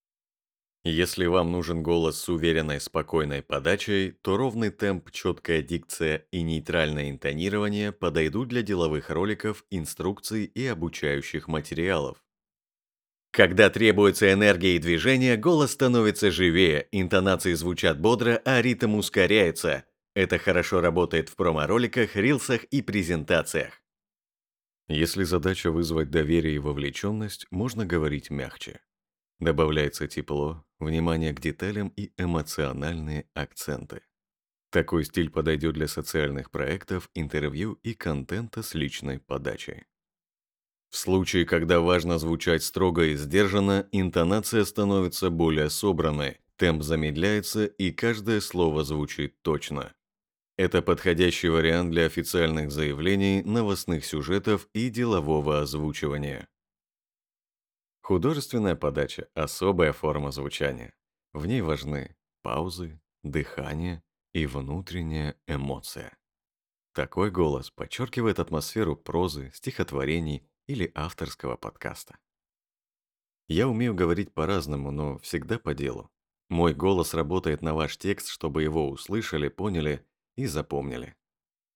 глубокий, проникновенный и качественный голос к вашим услугам. подкасты, реклама, стихи, ролики, рилсы